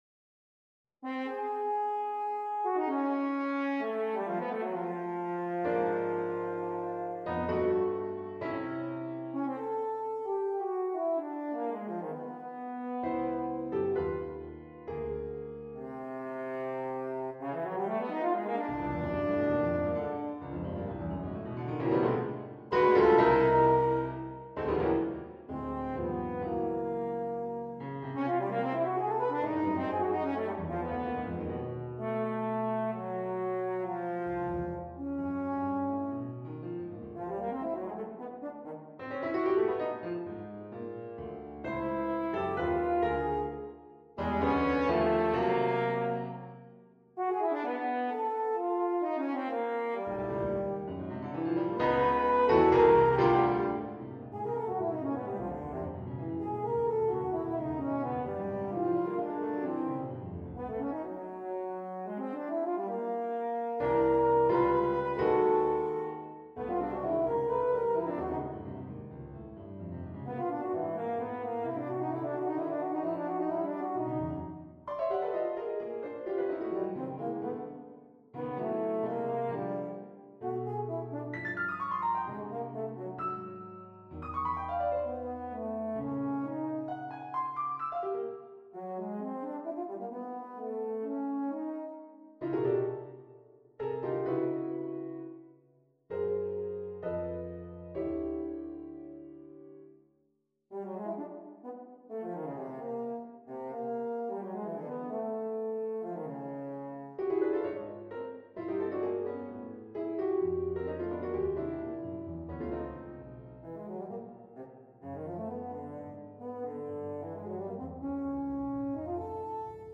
Sonata for Horn and Piano, Op.109 on a purpose-selected tone row 1.